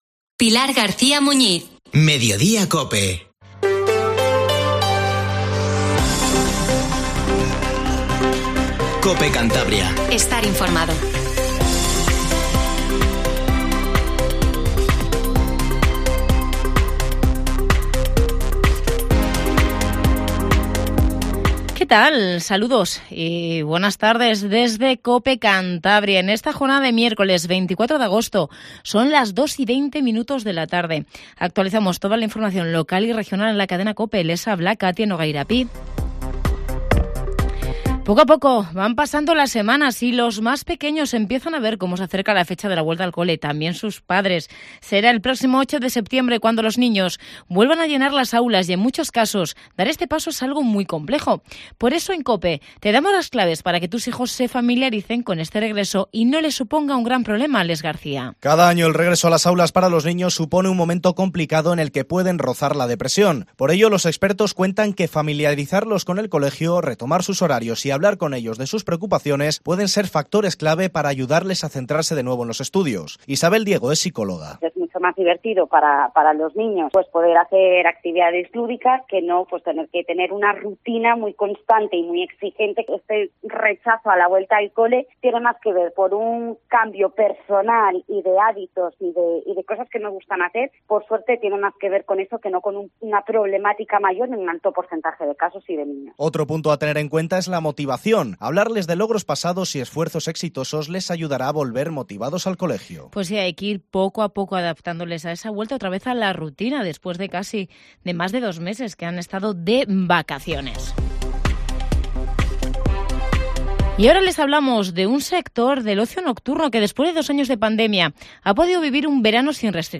Informativo Regional 1420